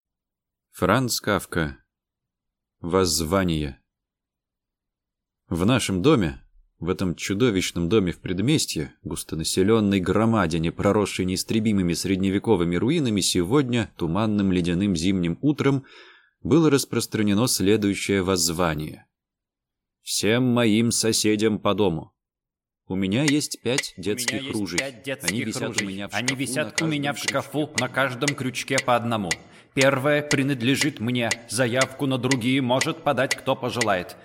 Аудиокнига Воззвание | Библиотека аудиокниг